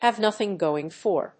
アクセントhàve nóthing góing for…